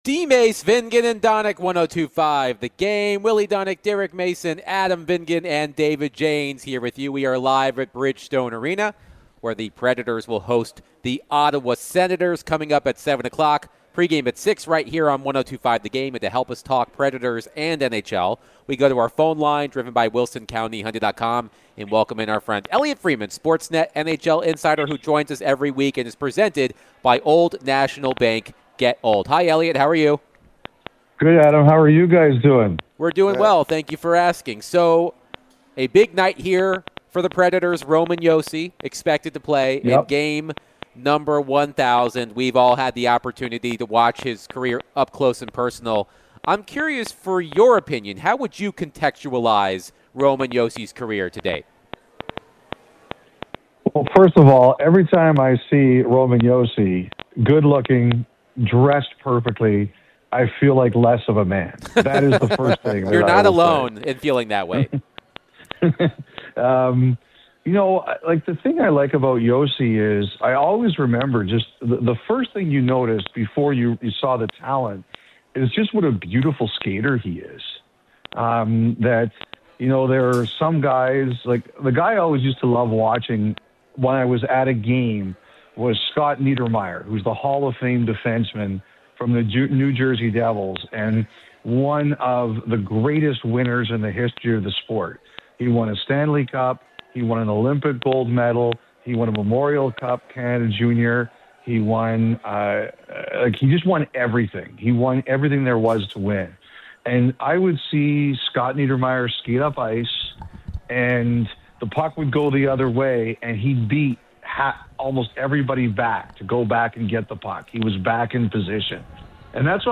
NHL Insider Elliotte Friedman joined DVD to discuss all things NHL, Nashville Predators, Roman Josi 1,000th career game, preds players trade values, and more